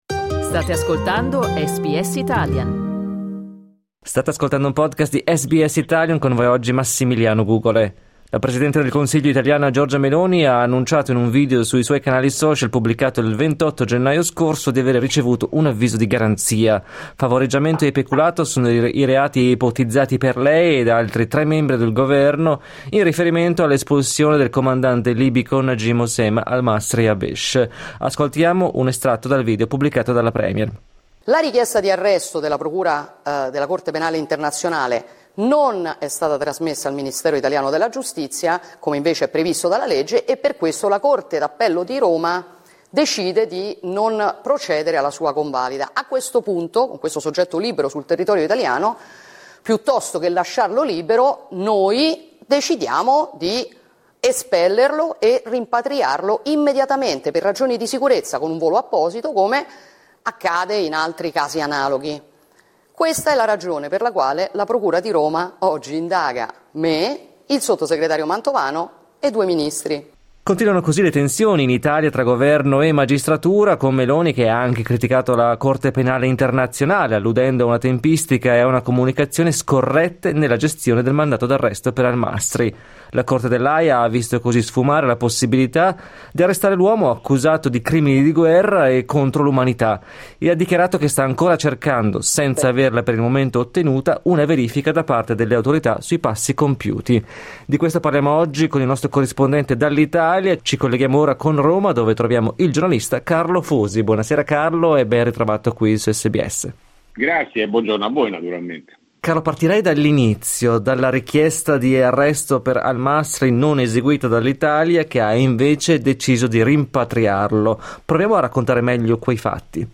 resoconto